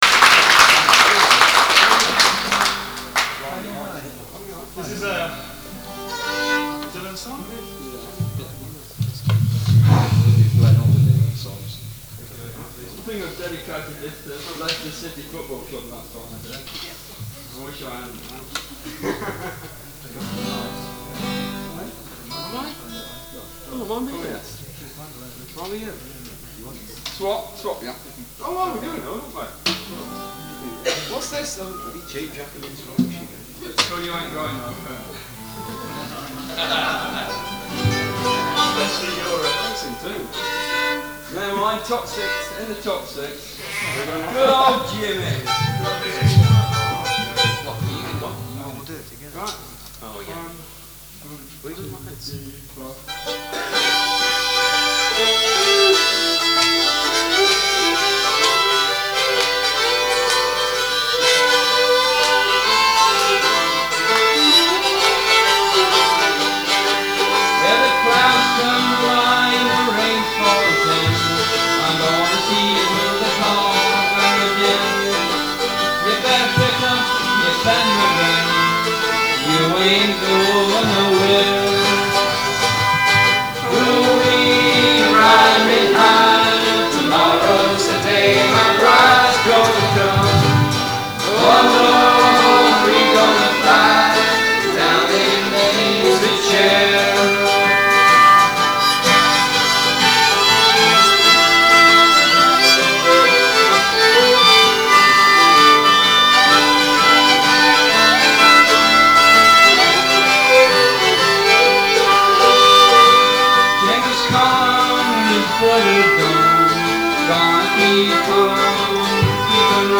Guitars, Bass, Percussion, Vocals
Fiddle, Vocals